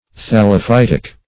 Thal`lo*phyt"ic, a.